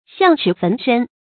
發音讀音
成語簡拼 xcfs 成語注音 ㄒㄧㄤˋ ㄔㄧˇ ㄈㄣˊ ㄕㄣ 成語拼音 xiàng chǐ fén shēn 發音讀音 感情色彩 中性成語 成語用法 緊縮式；作賓語；比喻人因為有錢財而招禍 成語結構 緊縮式成語 產生年代 古代成語 成語例子 正是：山木自寇， 象齒焚身 。